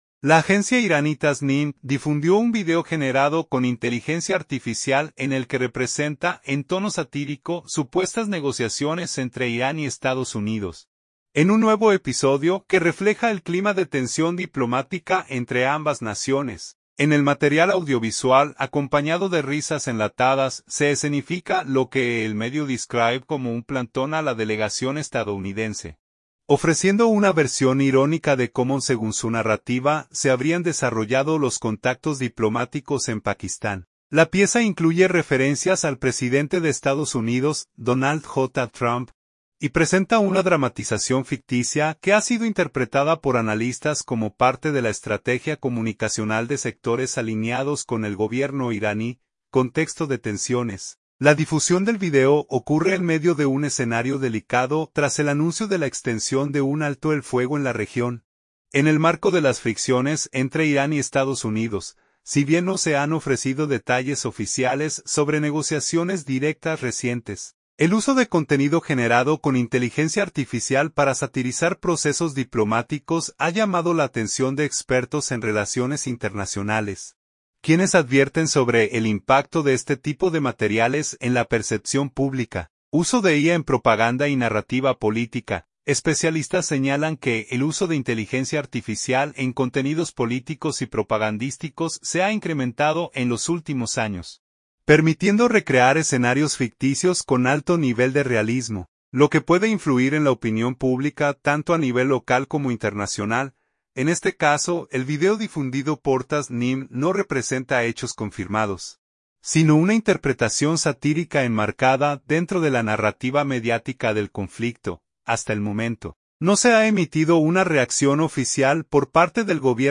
InfoENN – Teherán / Washington. — La agencia iraní Tasnim difundió un video generado con inteligencia artificial en el que representa, en tono satírico, supuestas negociaciones entre Irán y Estados Unidos, en un nuevo episodio que refleja el clima de tensión diplomática entre ambas naciones.
En el material audiovisual, acompañado de risas enlatadas, se escenifica lo que el medio describe como un “plantón” a la delegación estadounidense, ofreciendo una versión irónica de cómo —según su narrativa— se habrían desarrollado los contactos diplomáticos en Pakistán.